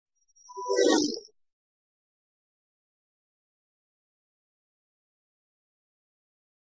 Small Synth Vehicle, Fast Musical Pulse By.